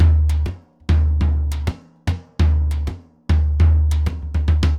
Surdo 1_Samba 100_2.wav